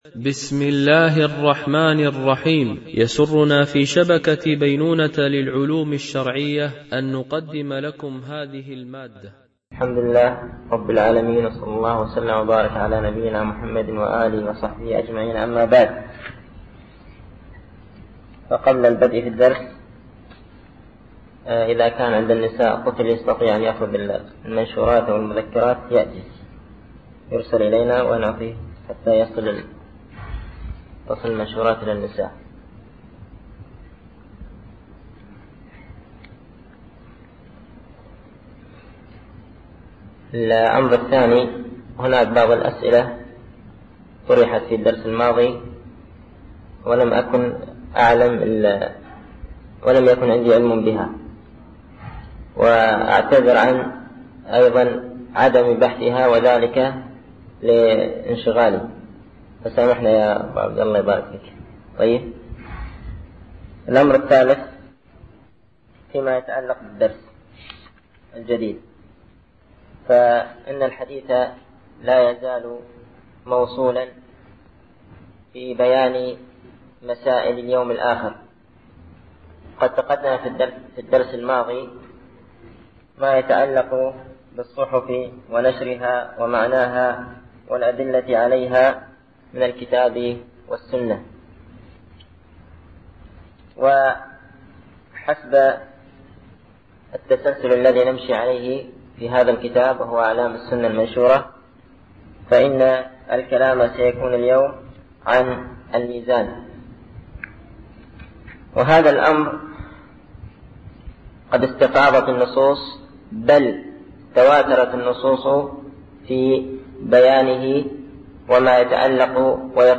) الألبوم: شبكة بينونة للعلوم الشرعية التتبع: 46 المدة: 35:34 دقائق (8.18 م.بايت) التنسيق: MP3 Mono 22kHz 32Kbps (CBR)